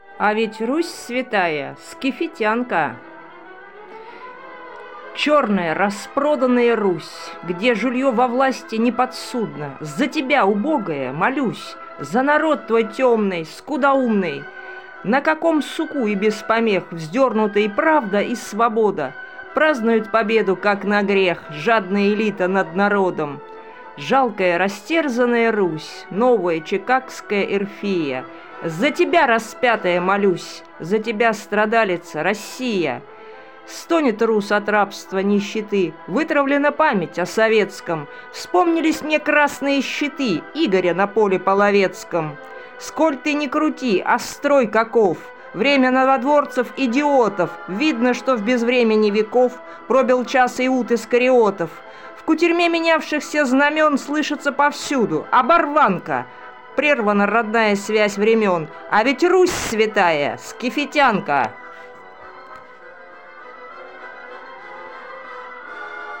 Музыка классики Озвучка автора